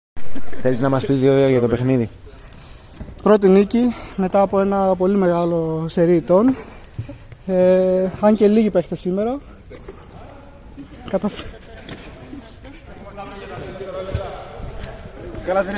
GAME INTERVIEWS:
(Παίκτης ΜΜ Μarine)